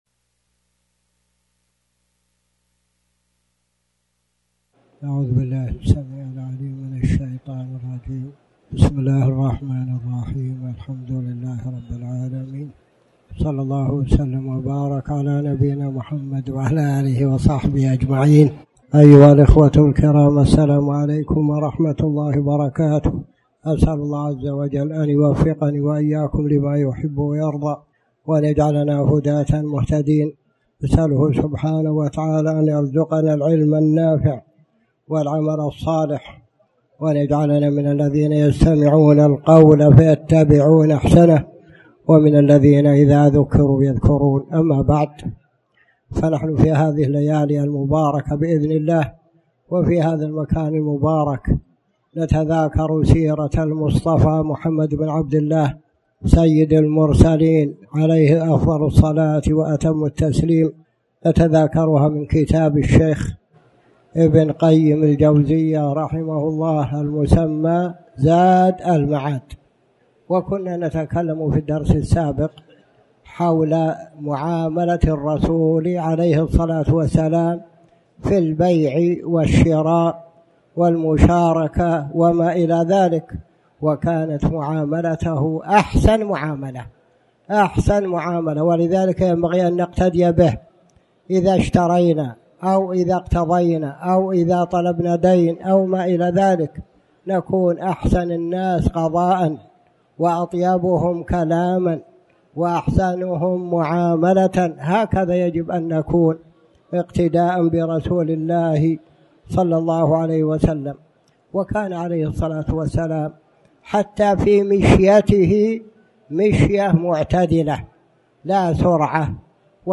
تاريخ النشر ١٥ رجب ١٤٣٩ هـ المكان: المسجد الحرام الشيخ